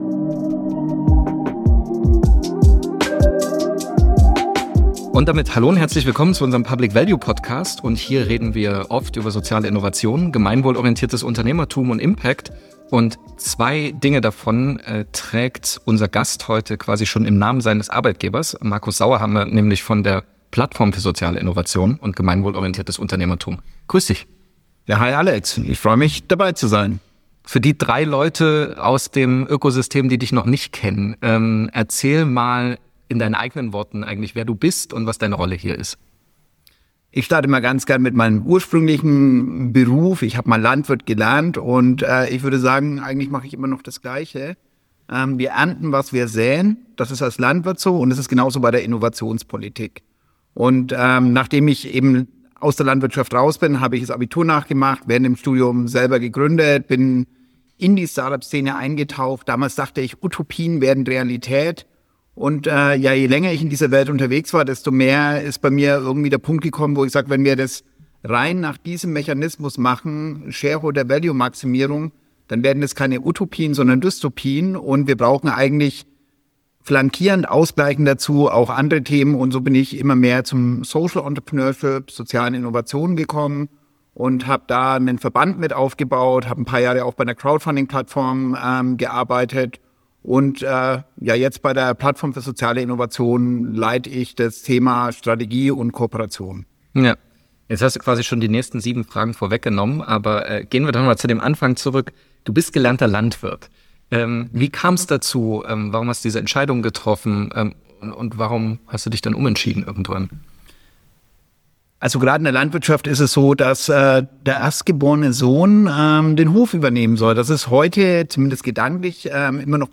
Gesellschaftliche Innovationen und Social Entrepreneurship: Ein Gespräch